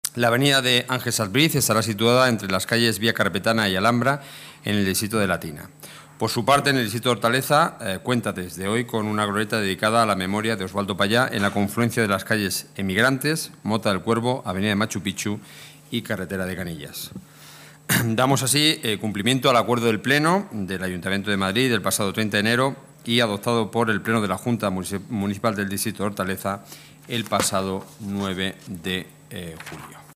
Nueva ventana:Declaraciones portavoz Gobierno municipal, Enrique Núñez: nuevos nombres calles